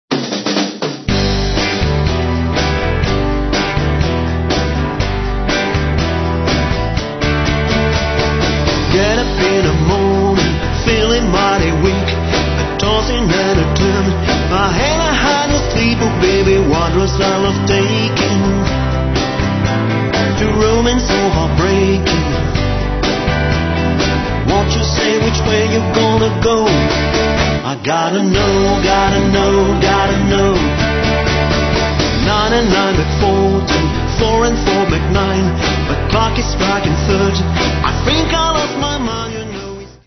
17 Titres country et Rock n’ roll